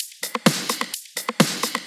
Index of /VEE/VEE Electro Loops 128 BPM
VEE Electro Loop 370.wav